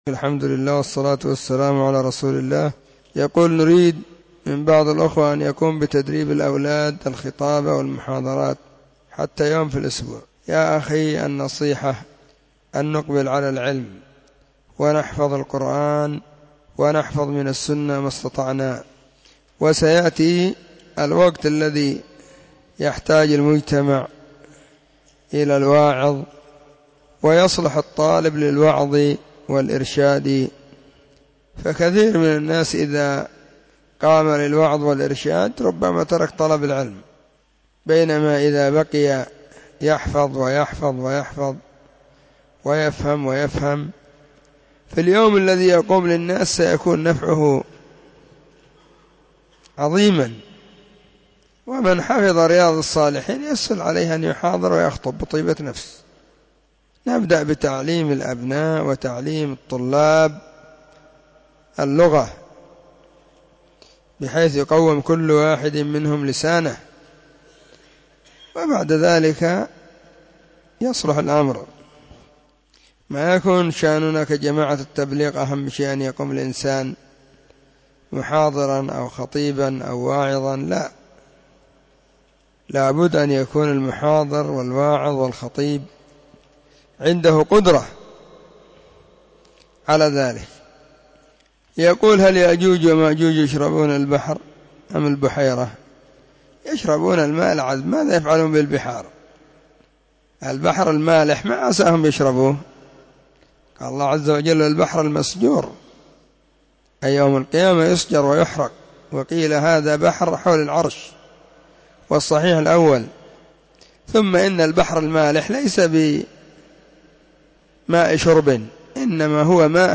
فتاوى, السبت 1 /ربيع الثاني/ 1443 هجرية, أسئلة ⭕ -8